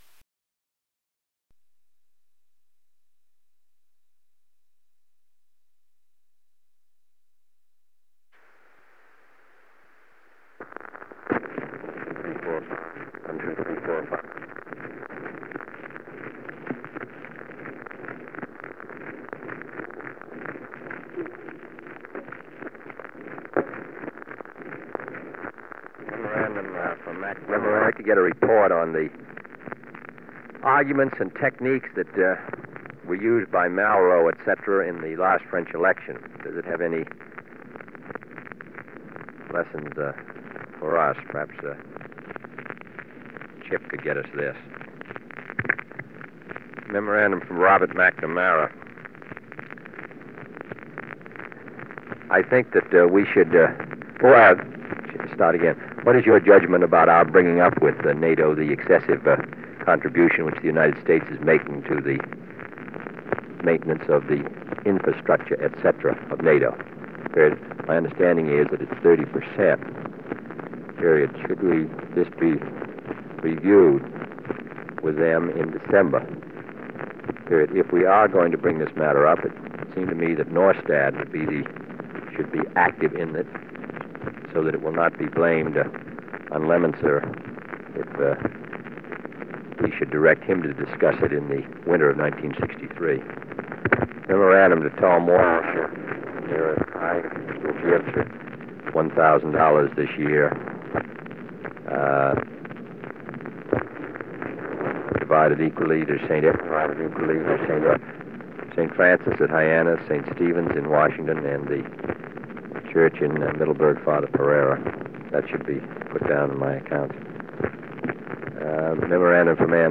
a tape-recorded observation, better coordination with Eisenhower’s team might have spared him from disaster at the Bay of Pigs.
jfk_dict_xxxa.mp3